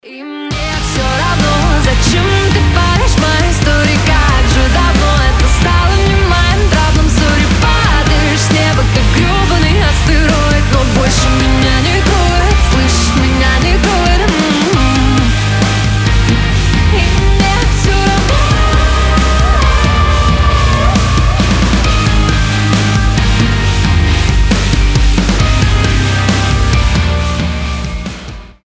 • Качество: 320, Stereo
громкие
Драйвовые
Pop Rock